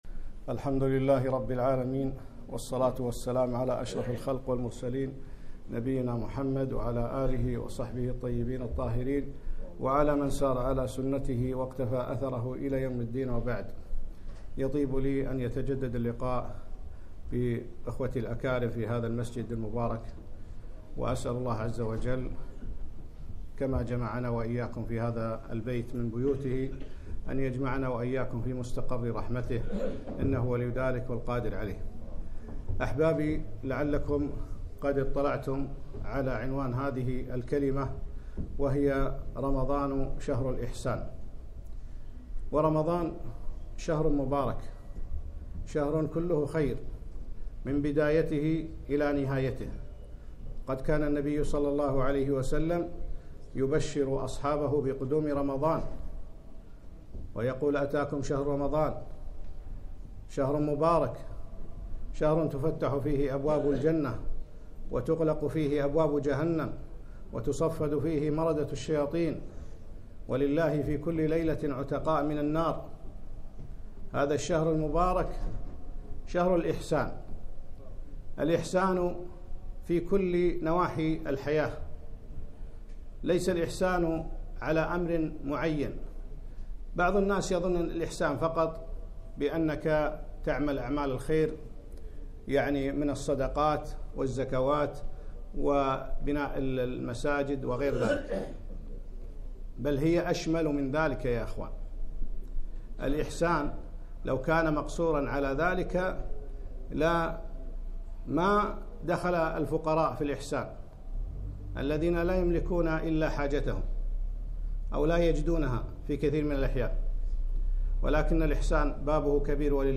كلمة - رمضان شهر الإحسان